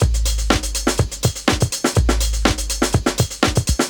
break